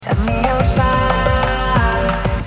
神秘忧伤